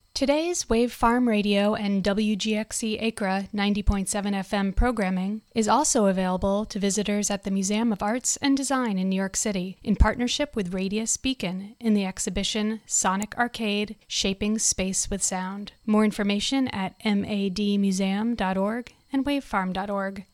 Wave Farm WGXC Museum of Arts and Design Radius Beacon Station ID (Audio)